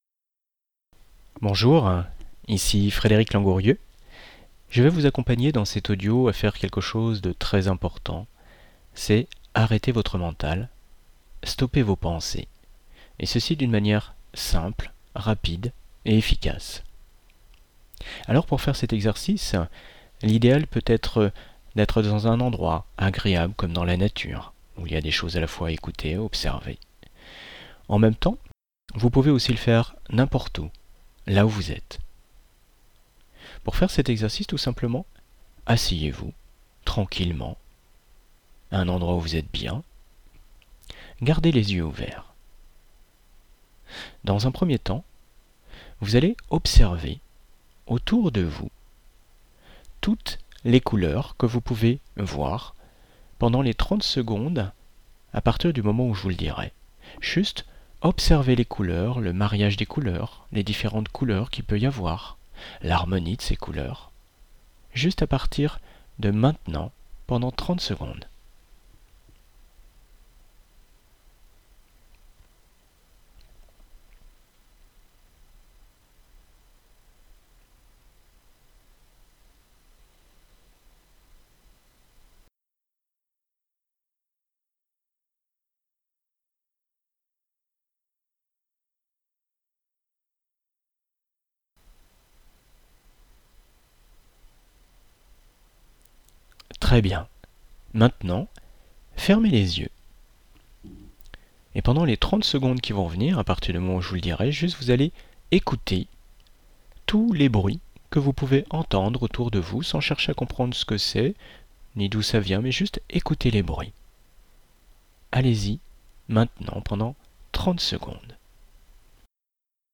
Cet audio n’est pas une séance d’hypnose, mais plutôt une méthode de méditation facile, agréable et efficace pour arrêter vos pensées.